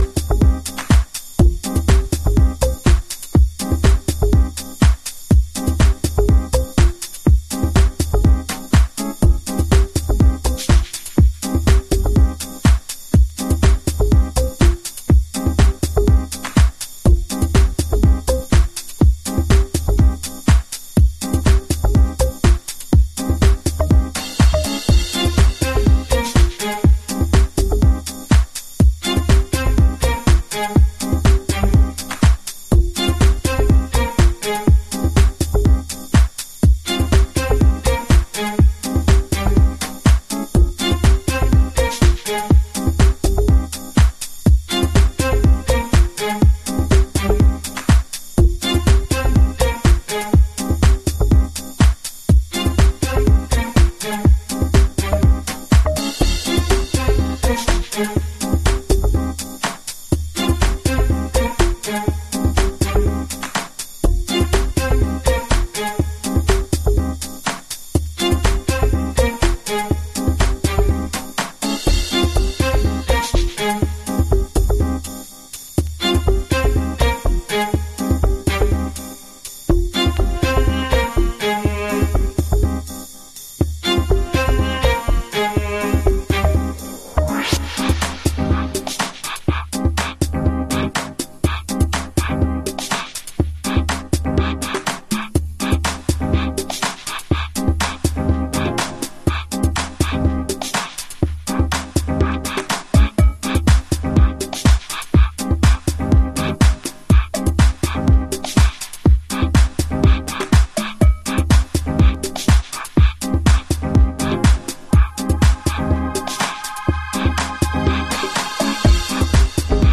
House / Techno
フェティッシュなシンセの揺らぎや、独特のタイム感のブギー・ビートが魅力的。